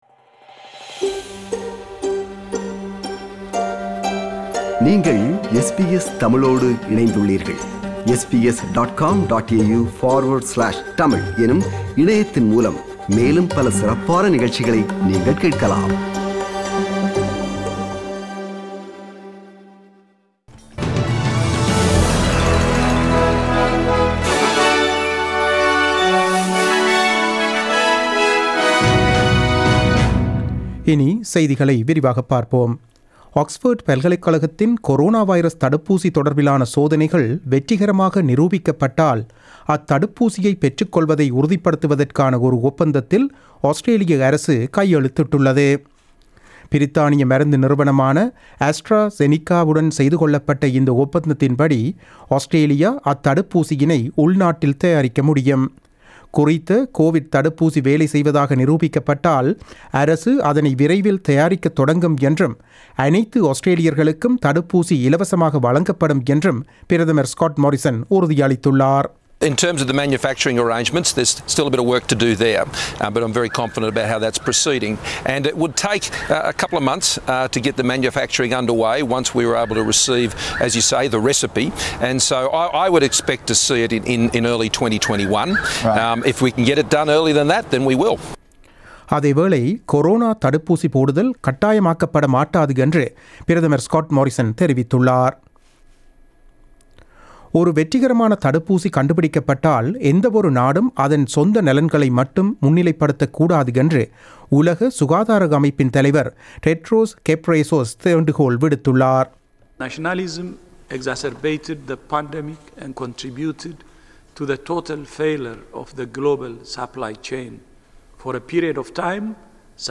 The news bulletin broadcasted on 19 August 2020 at 8pm.